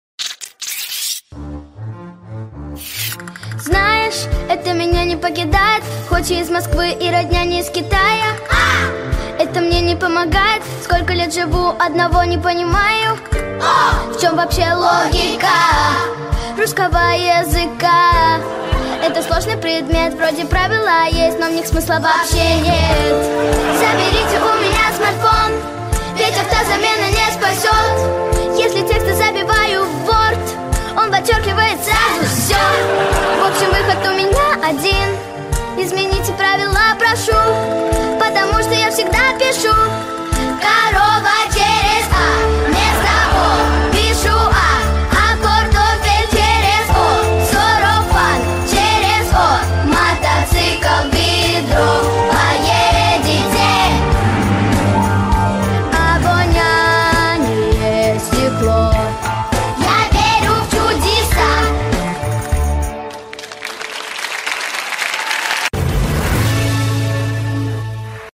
• Жанр: Детские песни
шуточная